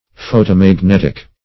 Photomagnetic \Pho`to*mag*net"ic\, a.
photomagnetic.mp3